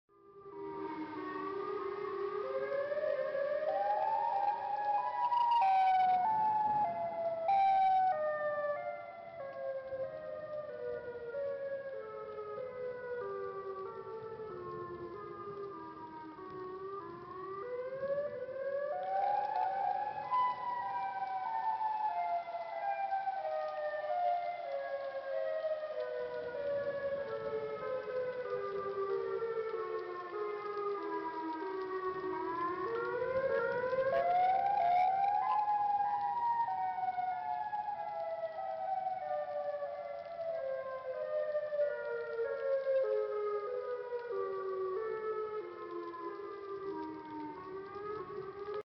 Sounds from the game Siren Head
On this page you can listen to sounds from the game siren head.